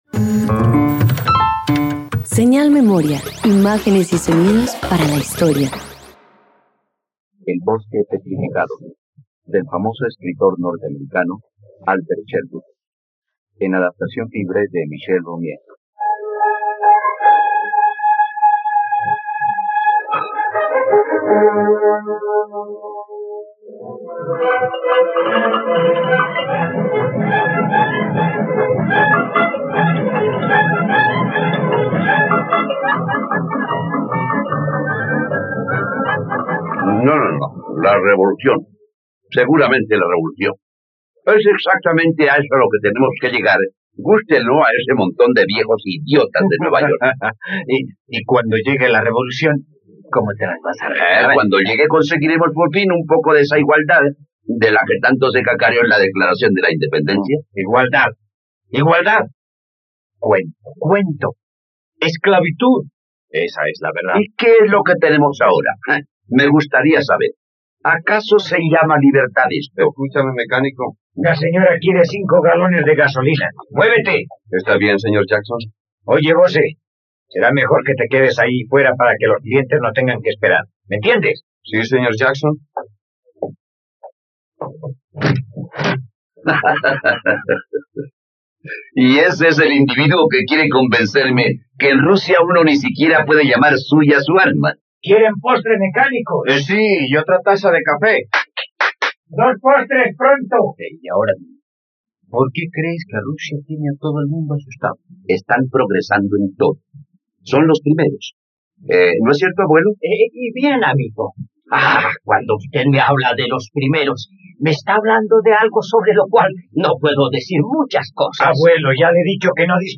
El bosque petrificado - Radioteatro dominical | RTVCPlay
..Radioteatro. Escucha la adaptación de la obra "El bosque petrificado" del dramaturgo estadounidense Robert Emmet Sherwood, disponible en RTVCPlay.